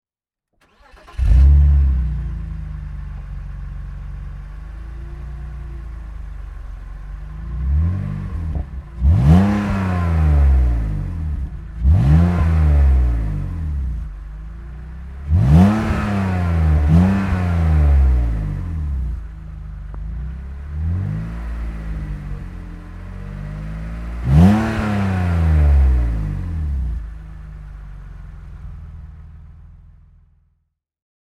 BMW Z3 3.0 Roadster (2001) - Starten und Leerlauf